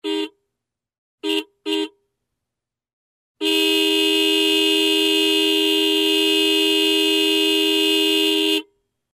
Сигнал мотоцикла
• Категория: Мотоциклы и мопеды